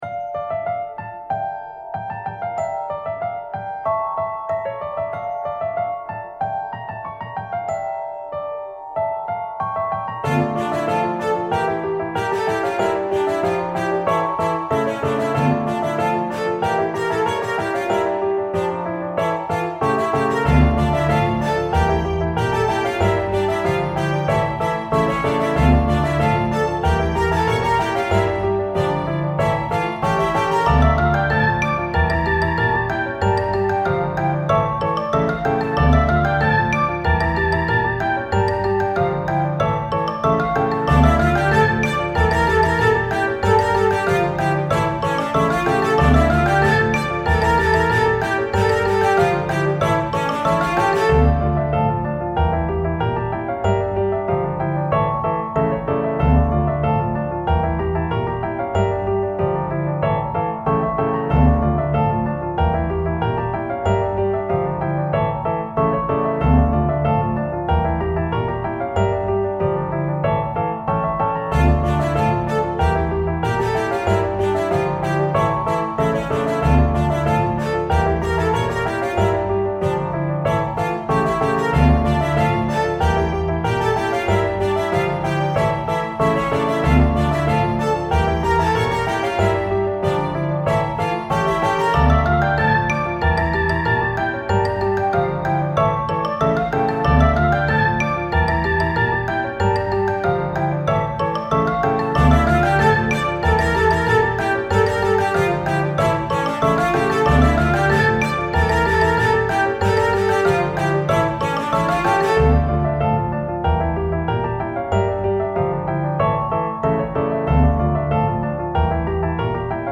不気味で怖い雰囲気のサスペンス系ホラーBGMです。 怪しい雰囲気も醸し出しています。